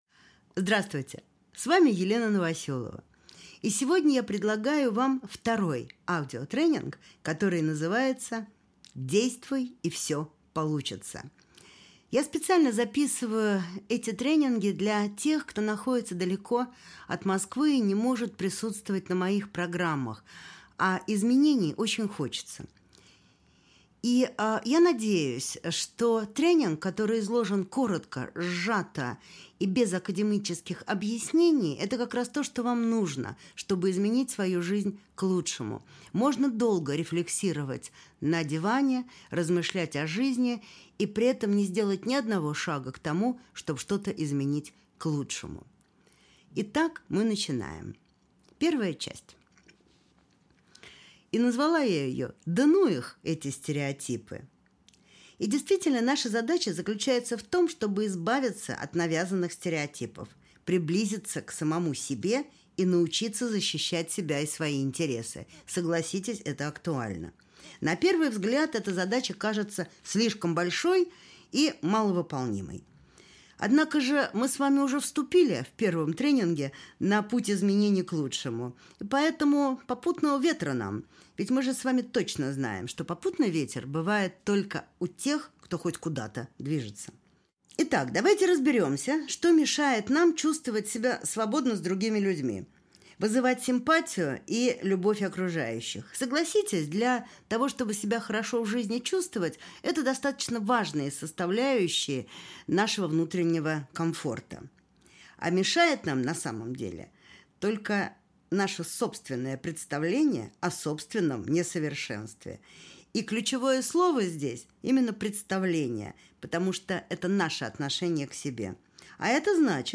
Аудиокнига Действуй, и всё получится!